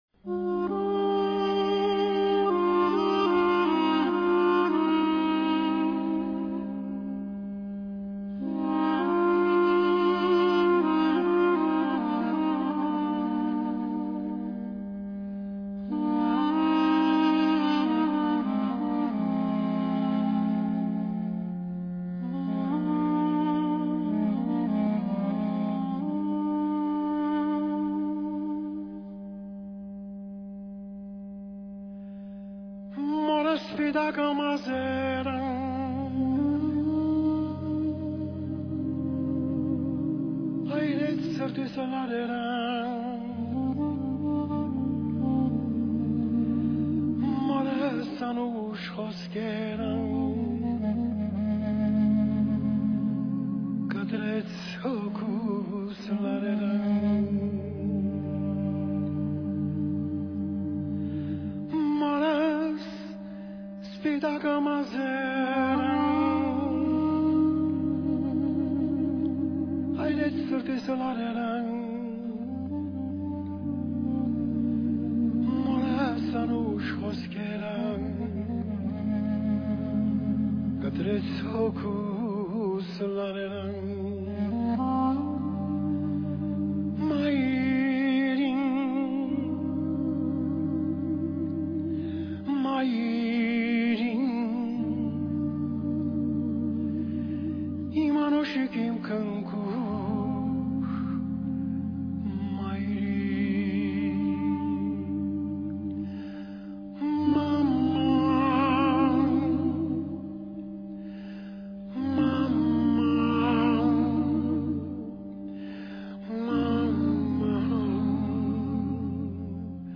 نوازنده ارمنی تبار «دودوک»